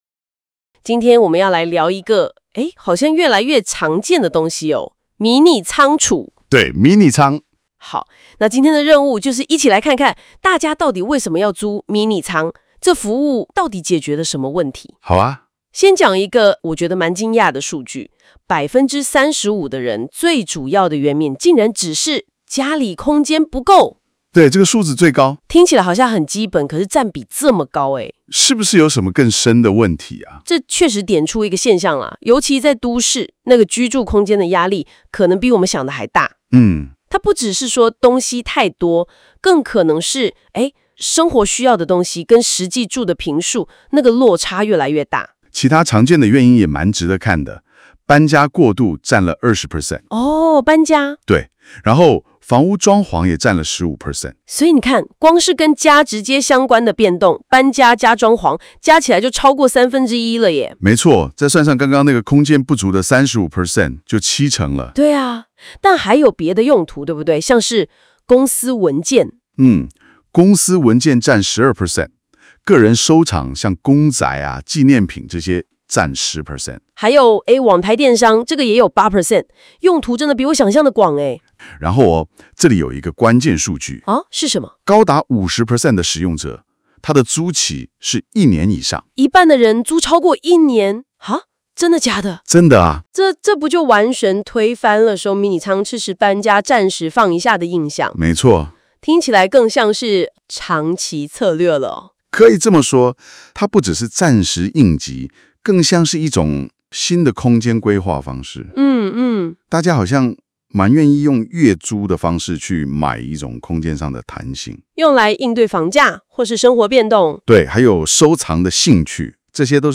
🔊Podcast 語音說明